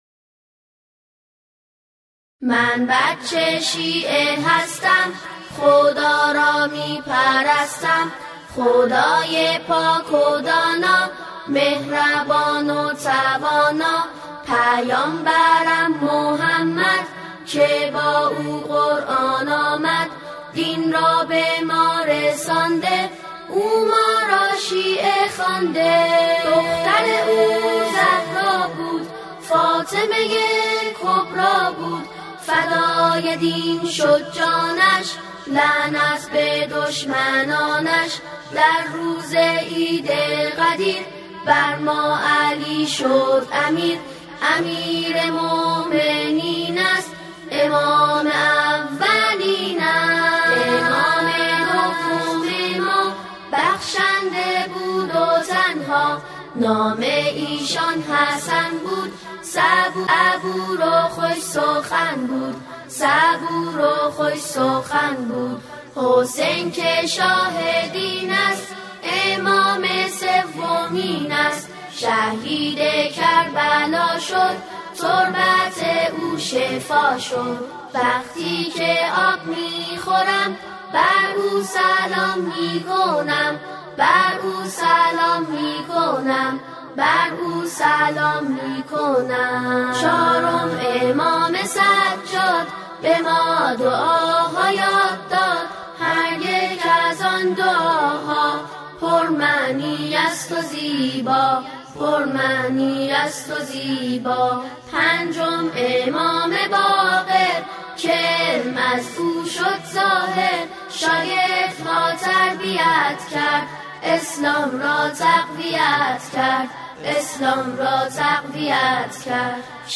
فایل صوتی من بچه شیعه هستم با صدای کودکانه
اعتقادات شیعه در قالب شعر و سرود